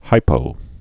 (hīpō) Informal